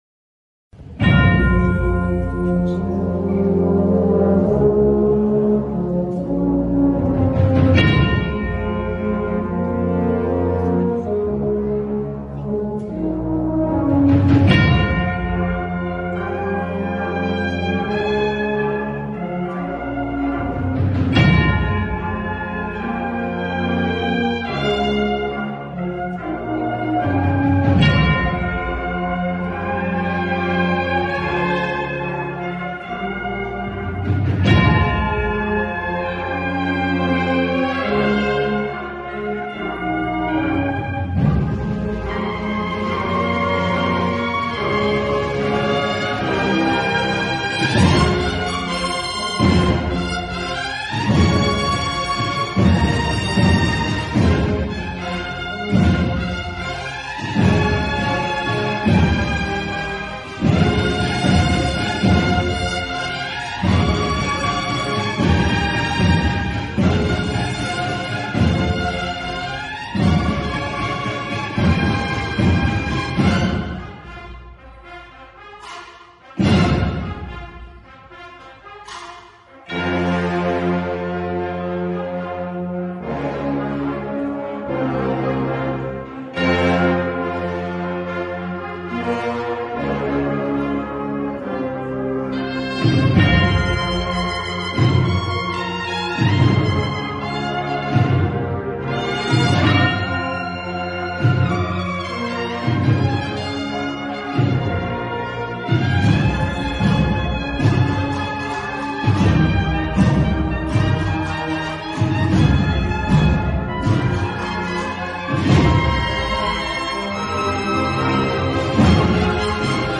Marchas dedicadas al Santísimo Cristo de la Redención